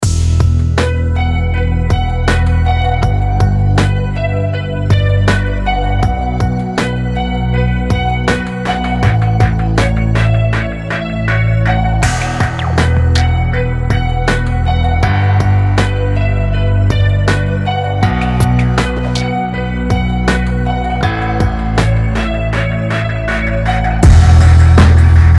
• Качество: 320, Stereo
атмосферные
Rap-rock
Electronic
без слов
минус